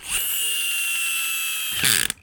• toy spring motor airplane small stop.wav
Recorded with a Steinberg Sterling Audio ST66 Tube, in a small apartment studio.
toy_spring_motor_airplane_small_stop_CW8.wav